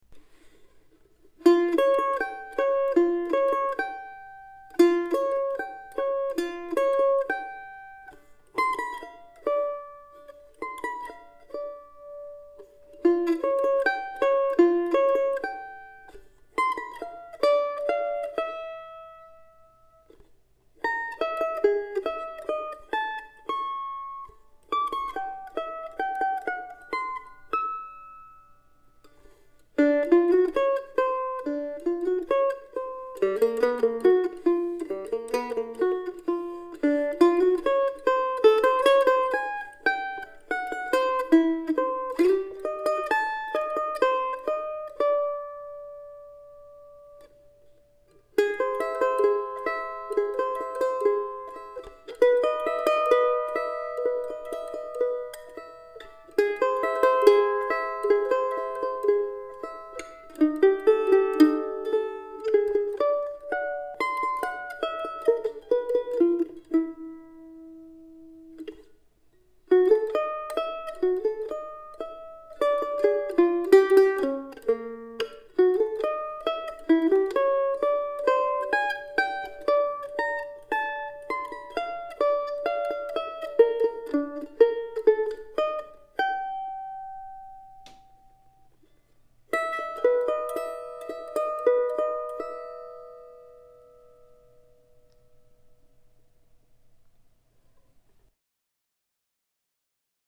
Bagatelle for Solo Mandolin, no. 3